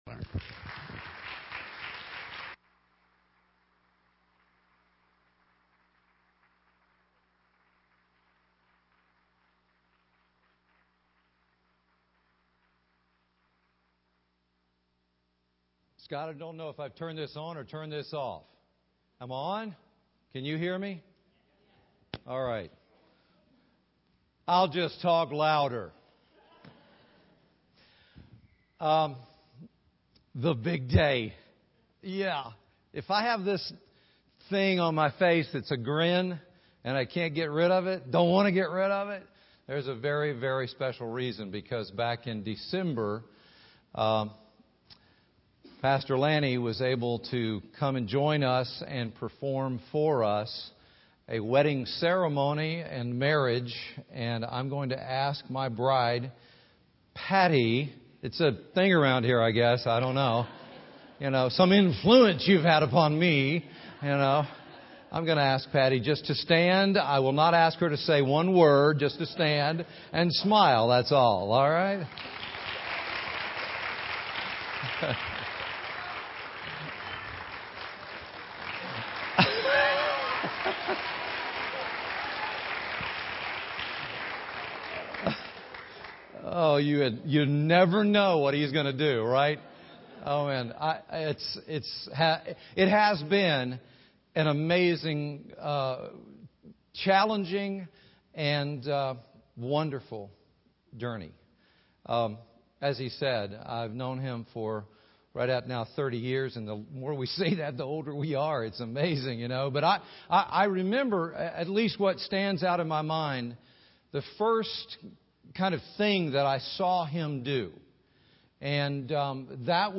Sunday morning sermon